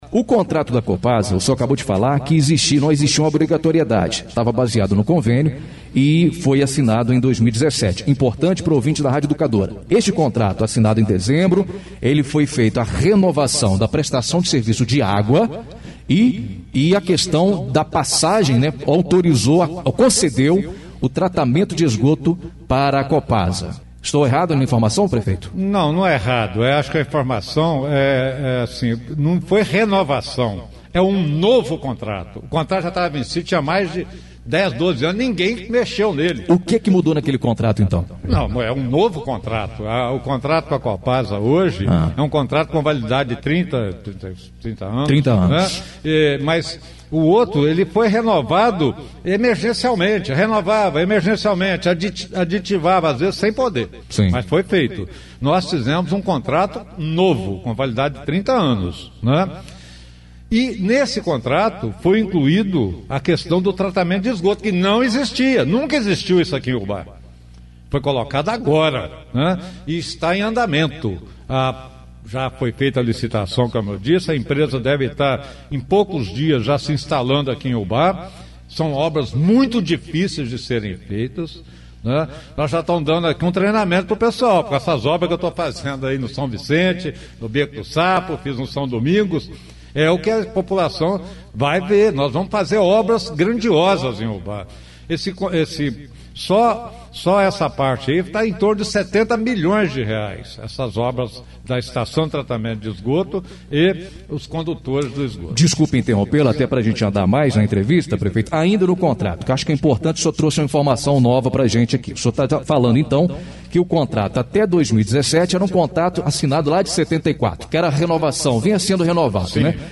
No período eleitoral municipal em 2020, em entrevista
o Prefeito Edson Teixeira Filho, explicou que não era possível revogar a taxa de esgotamento cobrado pela Copasa